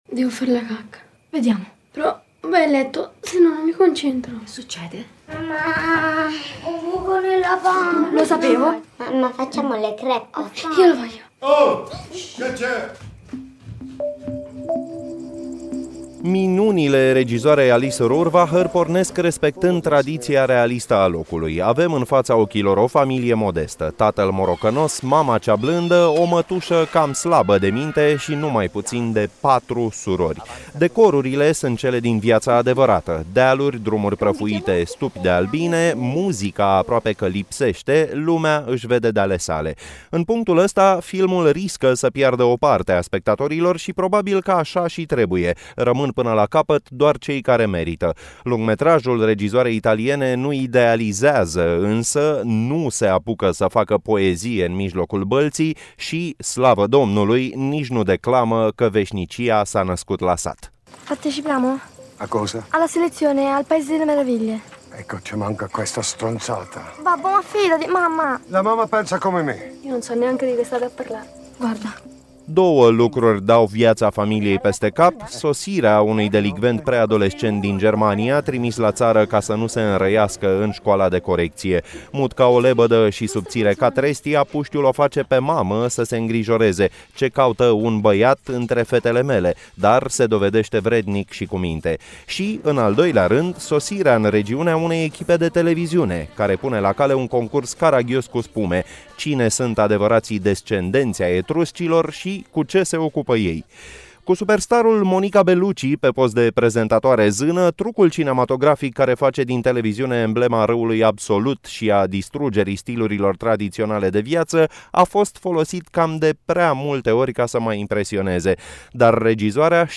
Cronică de film: Le Meraviglie, de Alice Rohrwacher (AUDIO)